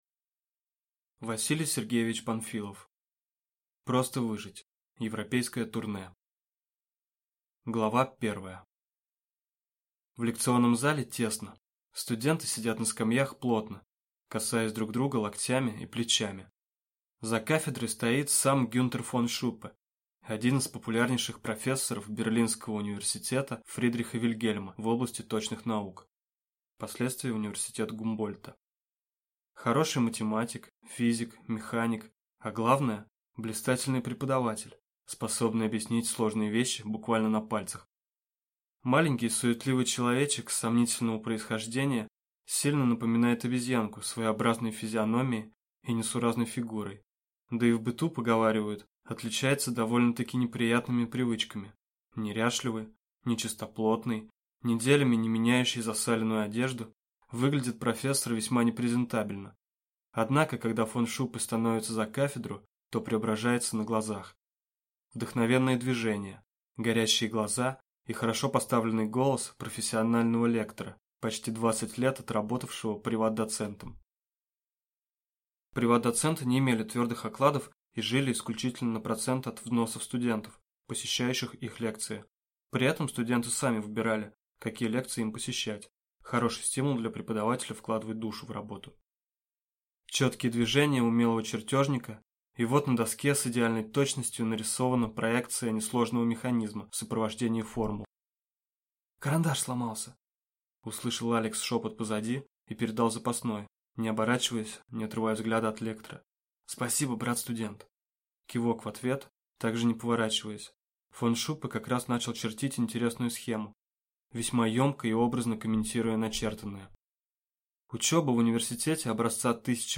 Аудиокнига Европейское турне | Библиотека аудиокниг